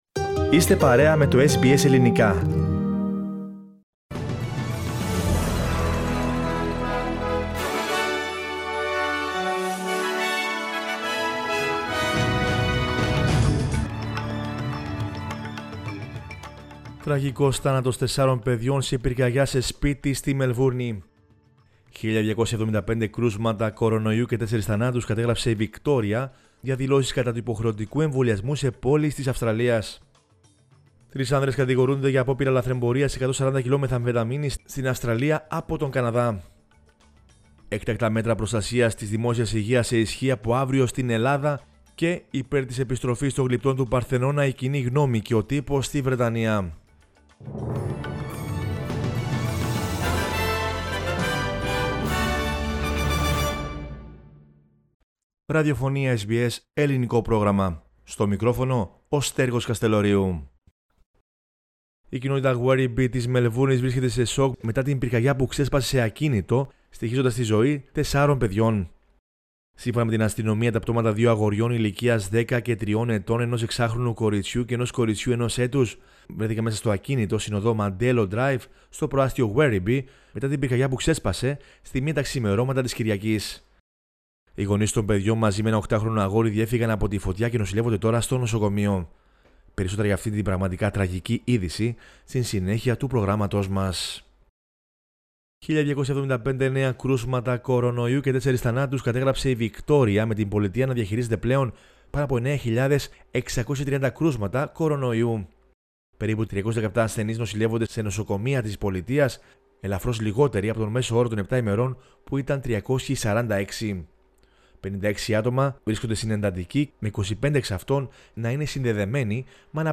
News in Greek from Australia, Greece, Cyprus and the world is the news bulletin of Sunday 21 November 2021.